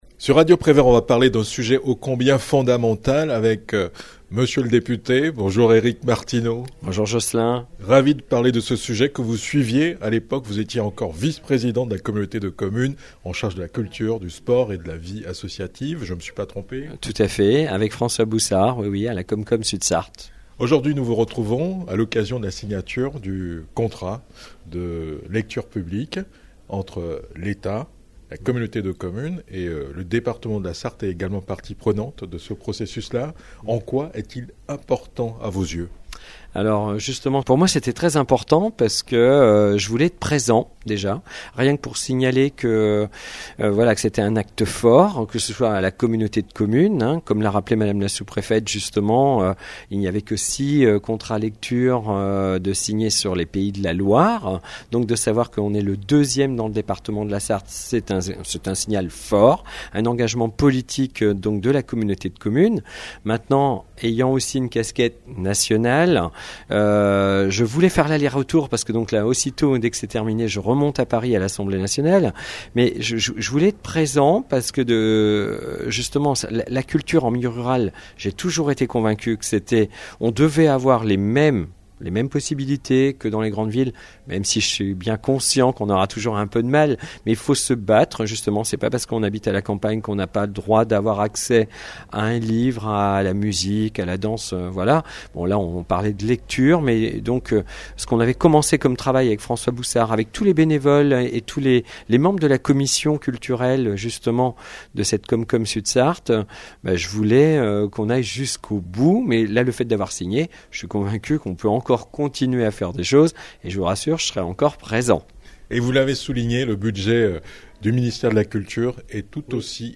A l'issue de la cérémonie, François Boussard, Président de l'intercommunalité, Véronique Ortet, sous-préfète de l'arrondissement de La Flèche, Véronique Rivron, 1ère vice-présidente du Conseil départemental de la Sarthe, présidente de la Commission vie associative, culture, sport, tourisme et patrimoine, et Eric Martineau, député de la 3e circonscription de la Sarthe ont mis en exergue le rôle fondamental de la lecture dans l'émancipation du citoyen.